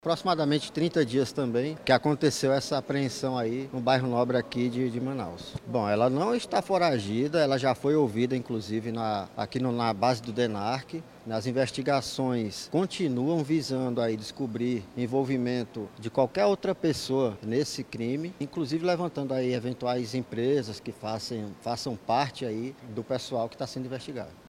Durante a coletiva, o delegado também detalhou uma apreensão realizada há cerca de 30 dias, em uma mansão no bairro Ponta Negra, onde foram encontrados 16 quilos de cocaína negra.